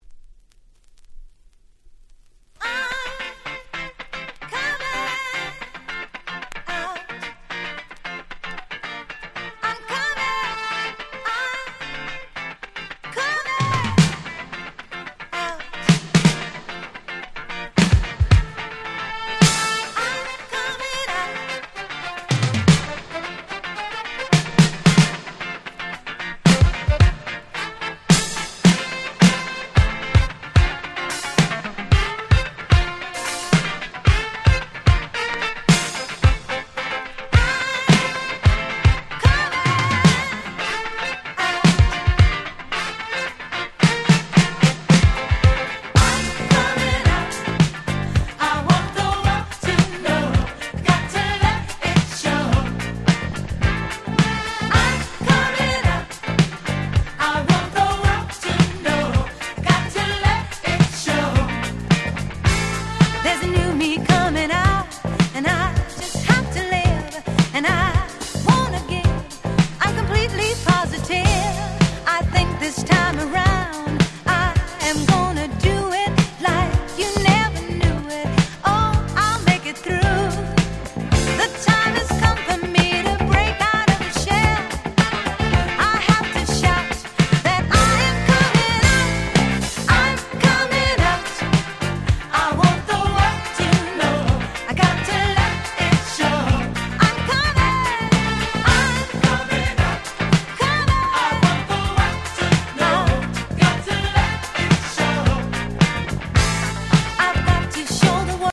Disco Boogieド定番！！